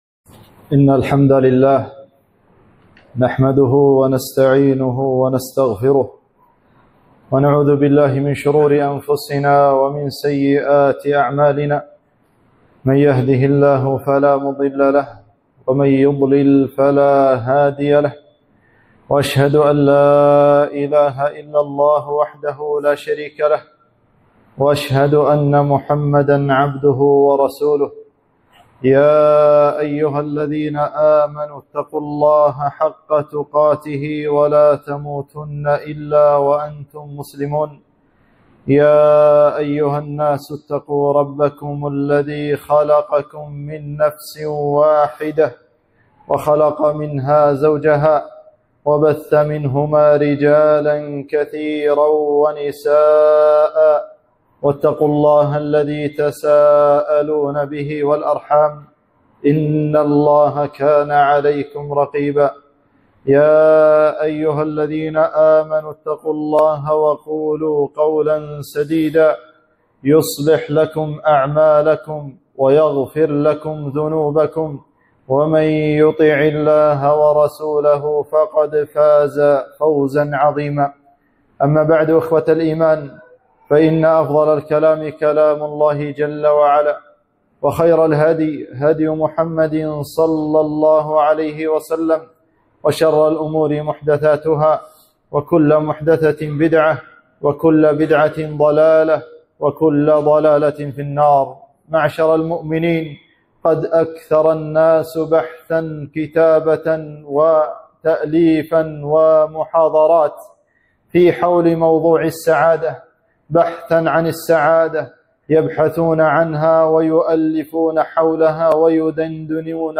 خطبة - أسباب السعادة (في تايلندا)